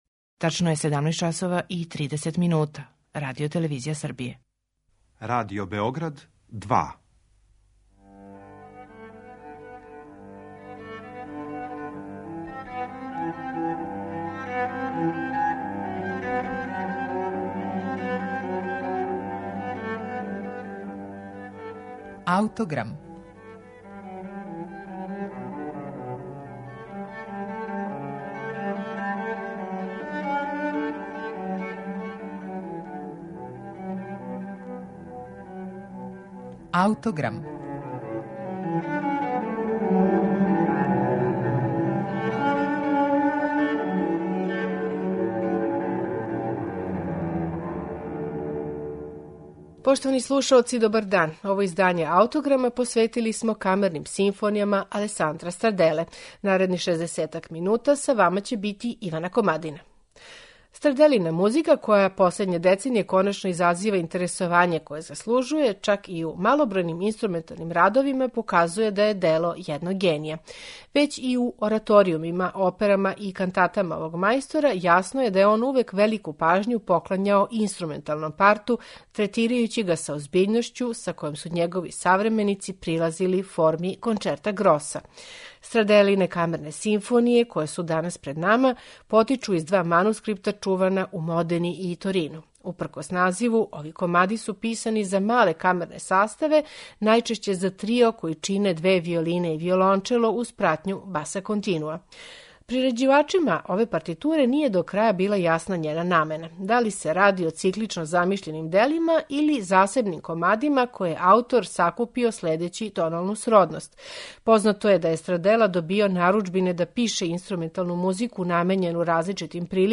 Тако су и његове камерне симфоније, упркос називу, писане за мале камерне саставе, најчешће за трио који чине две виолине и виолончело, уз пратњу баса континуа.
чембало и оргуље.